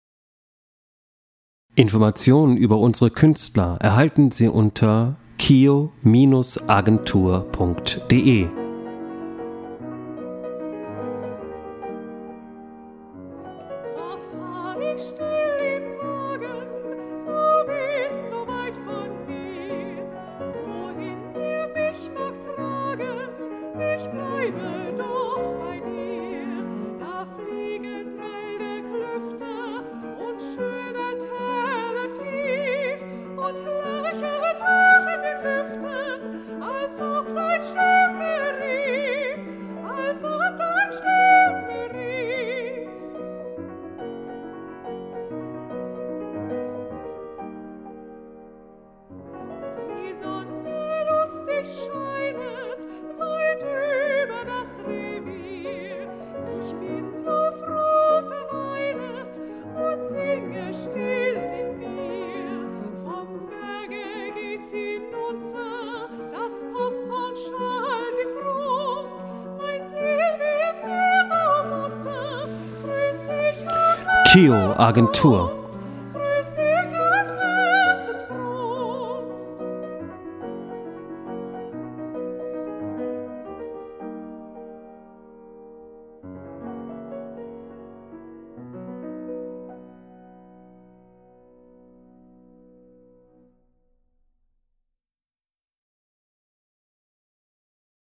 Lieder vom Ende des 20. Jahrhunderts
Liederabende